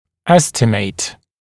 [‘estɪmeɪt][‘эстимэйт]оценивать, производить оценку, приблизительно определять